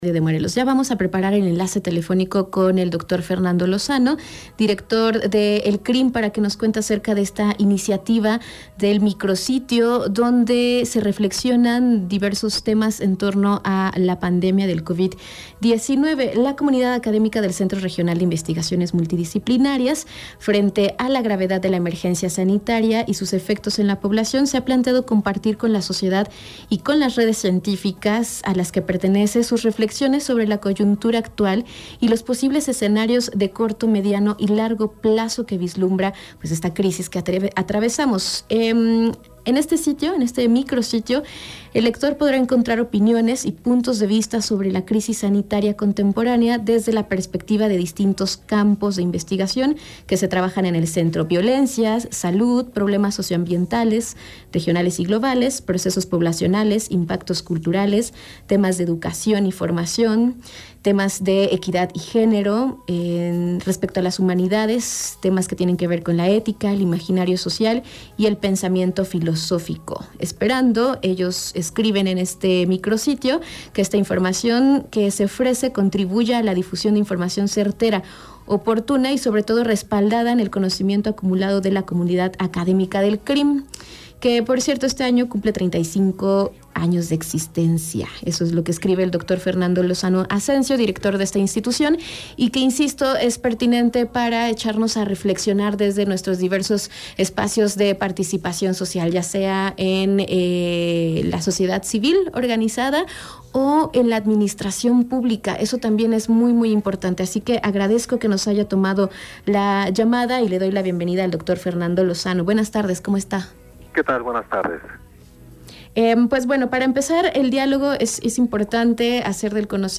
Entrevista
en la radio del Instituto Morelense de Radio y Televisión